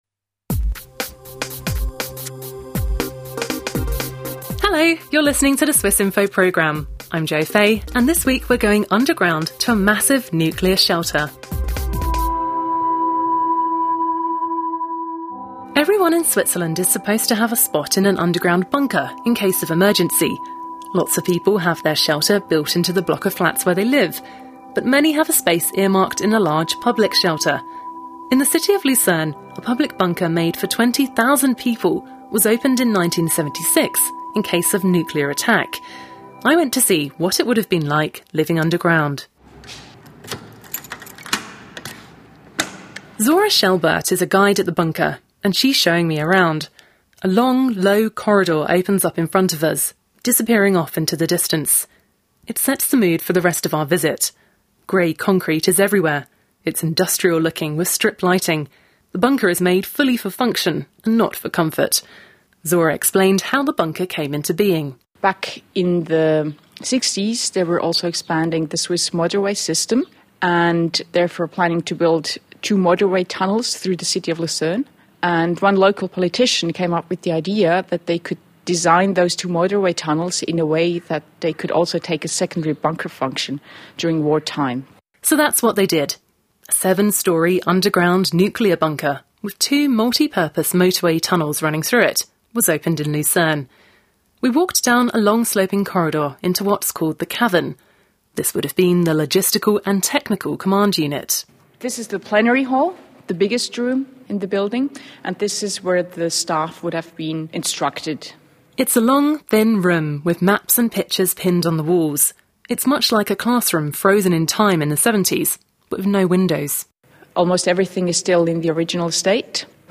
What would it have been like to be live deep below a Swiss city in case of a major catastrophe? We went into the depths of a nuclear bunker, built to shelter 20,000 people.